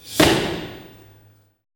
STAMPER   -R.wav